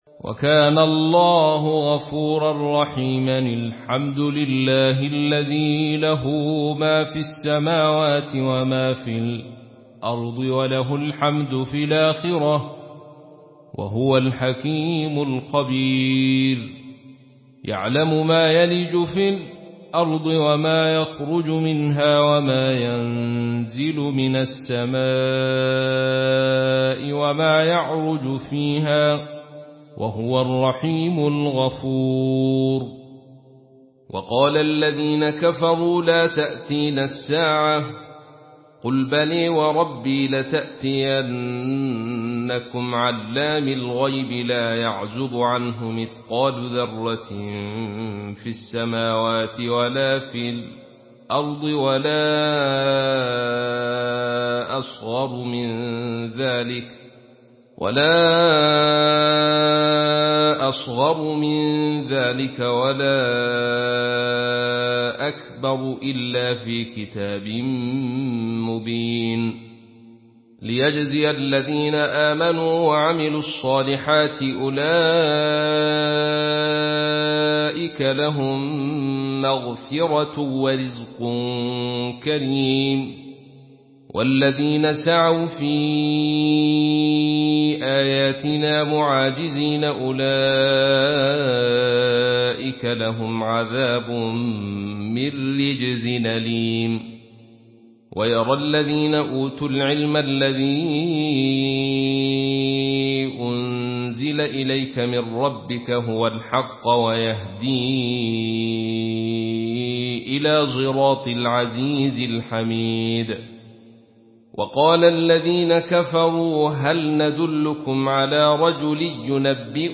Une récitation touchante et belle des versets coraniques par la narration Khalaf An Hamza.
Murattal